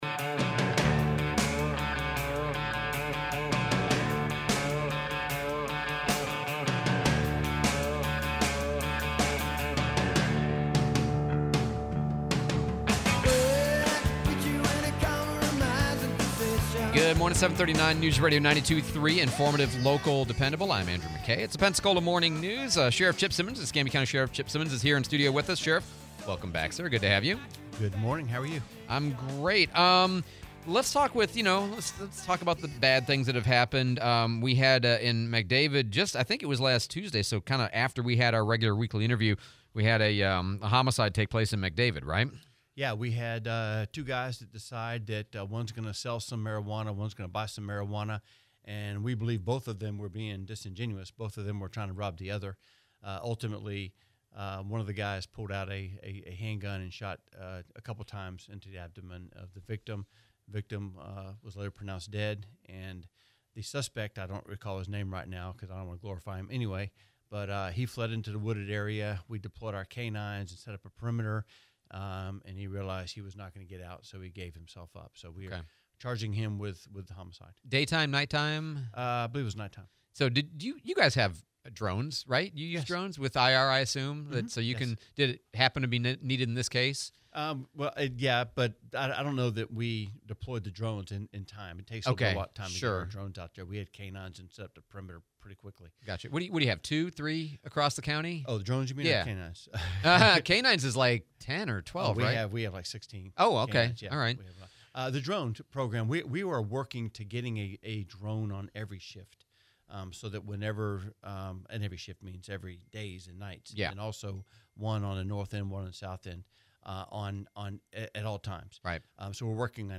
02/25/25 Sheriff Chip Simmons Interview